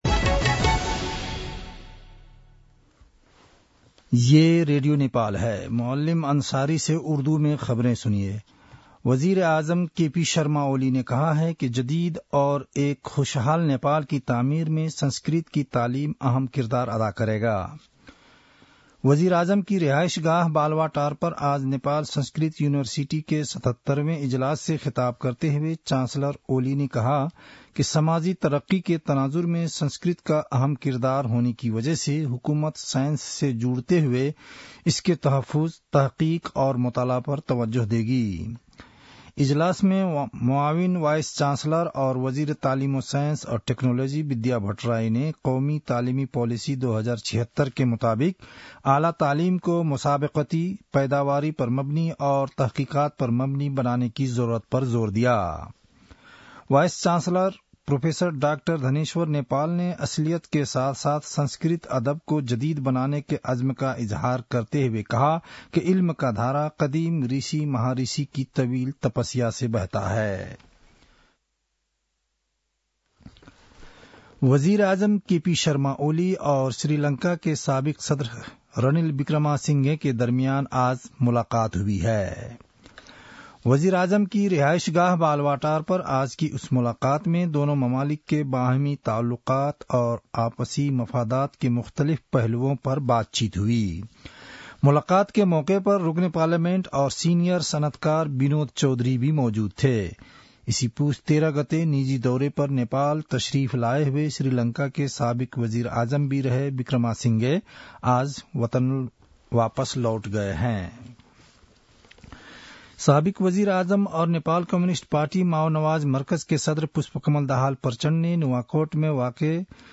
An online outlet of Nepal's national radio broadcaster
उर्दु भाषामा समाचार : १९ पुष , २०८१
Urdu-news-9-18.mp3